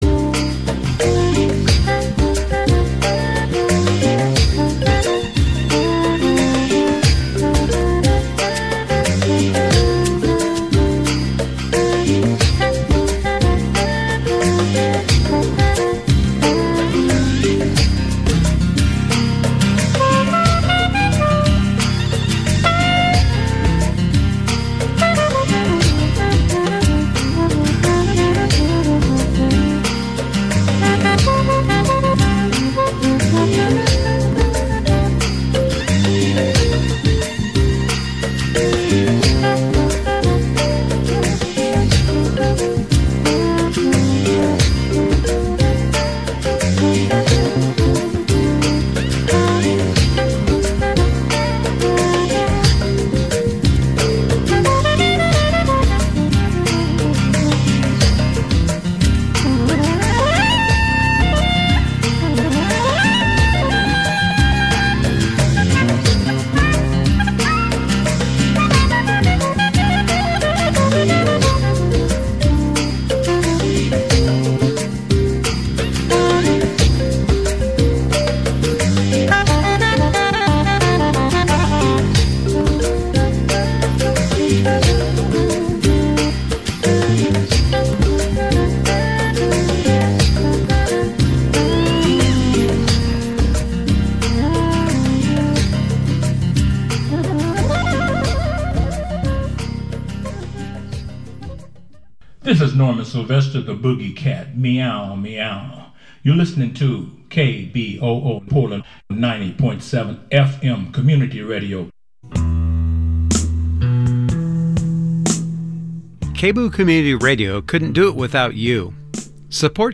Views, Reviews, and Interviews